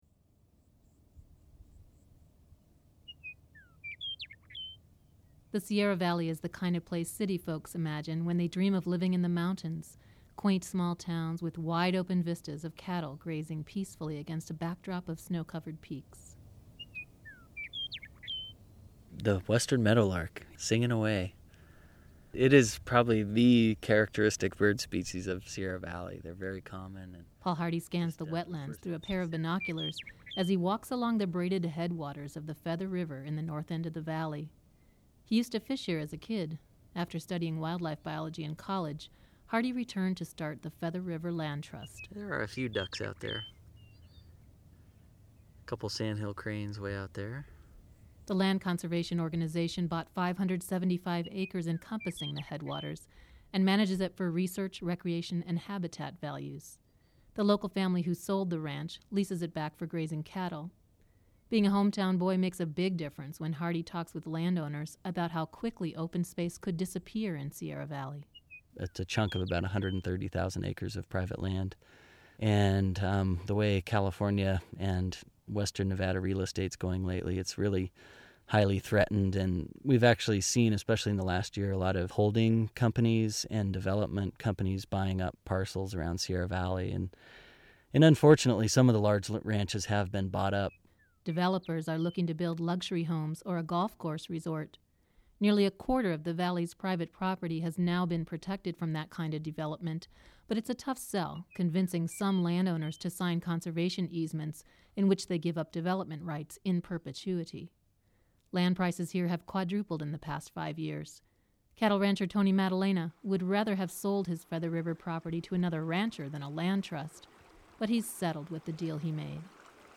Broadcast on The California Report November 10, 2006